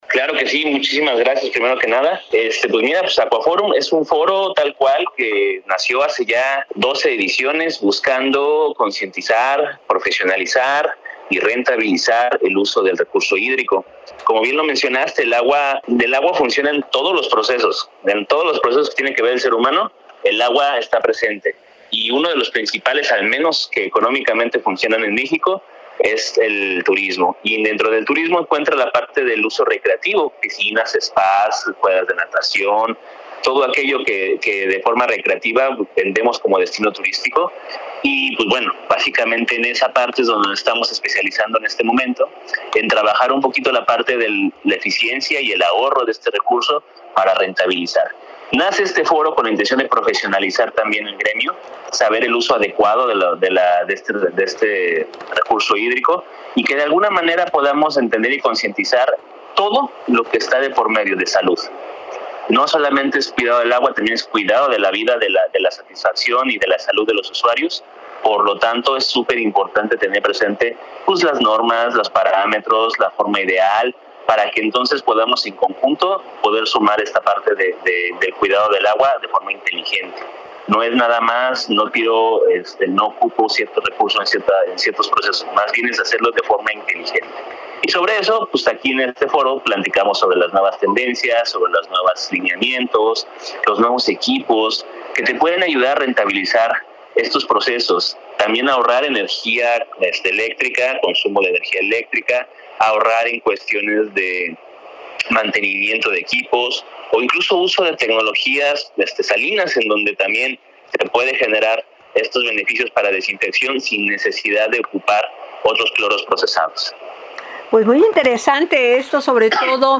22-ENTREVISTA-AGUA.mp3